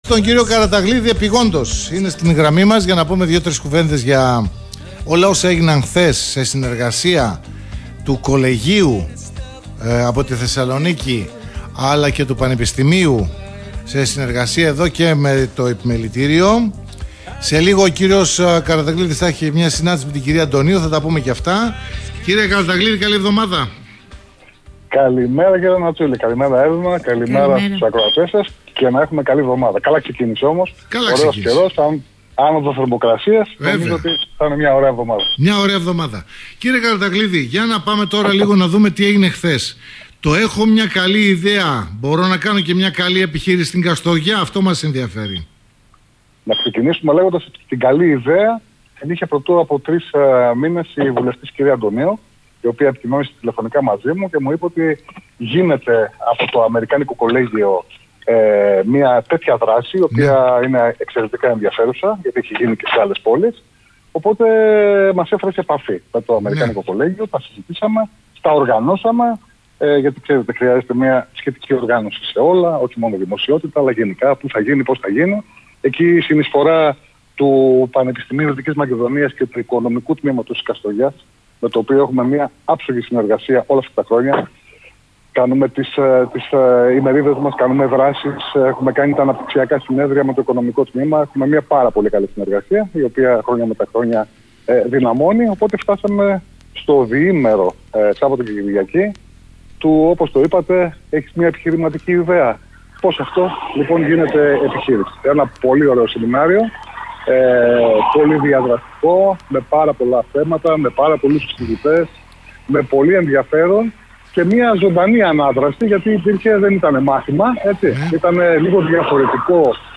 Μεγάλη άνθιση στον τομέα της υγείας” (συνέντευξη)